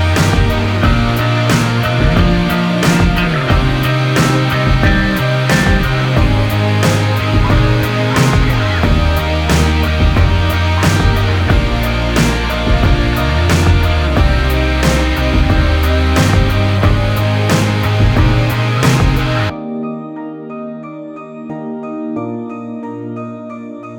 Minus Main Guitars Pop (2010s) 2:57 Buy £1.50